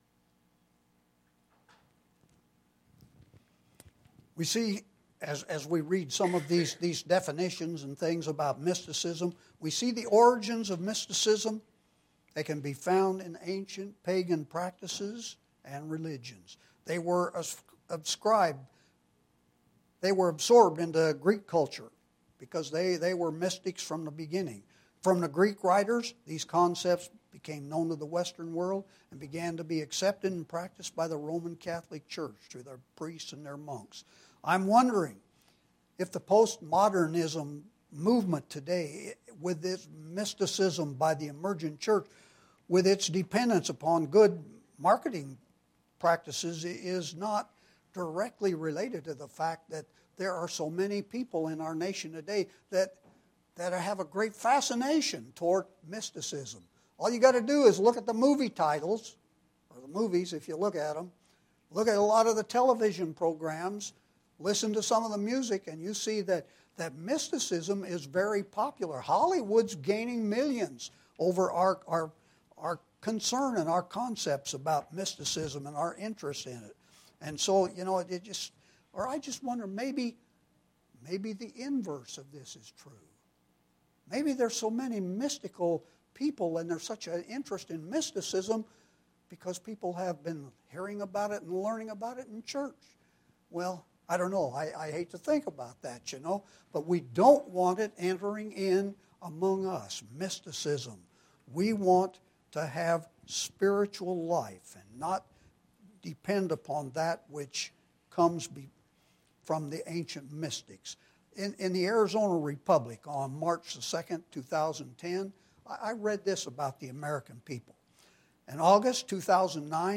This is an in-depth presentation to make us aware of Satan’s plan to reinvent church worship to a new post-modern way.